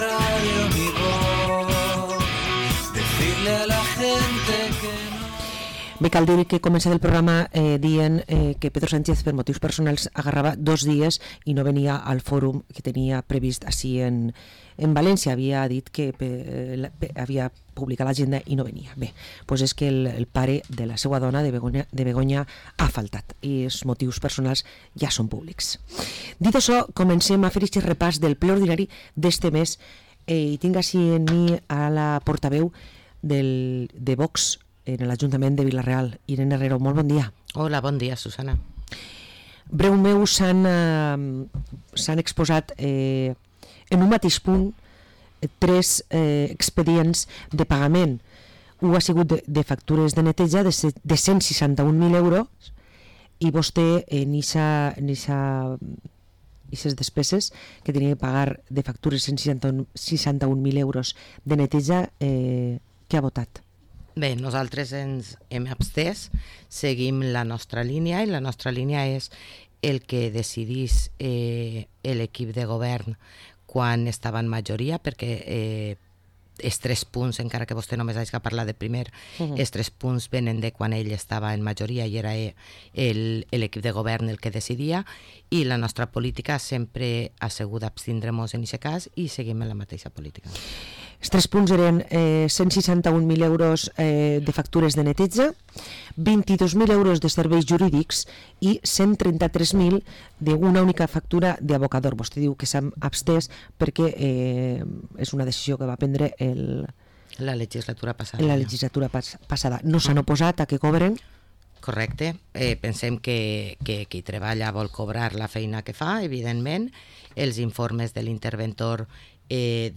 Parlem amb Irene Herrero, portaveu i regidora de VOX a l´Ajuntament de Vila-real